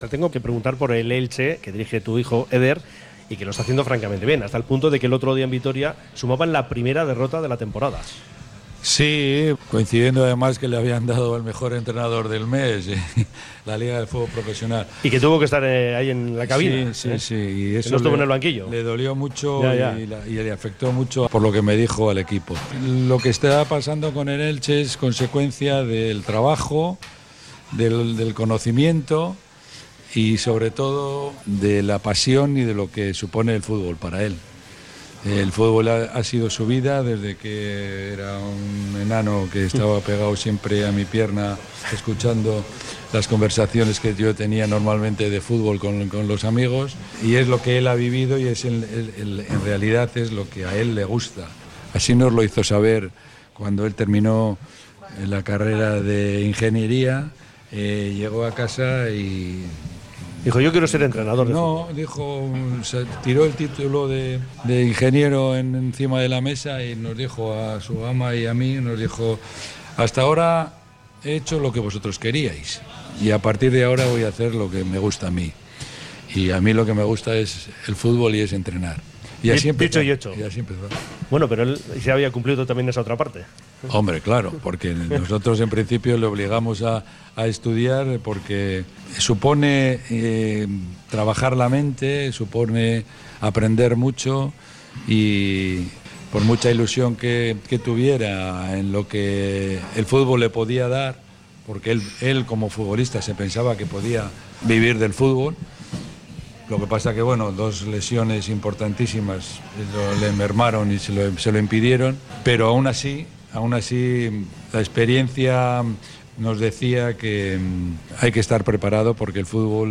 Manu Sarabia recuerda en Radio Popular-Herri Irratia los comienzos de Eder como entrenador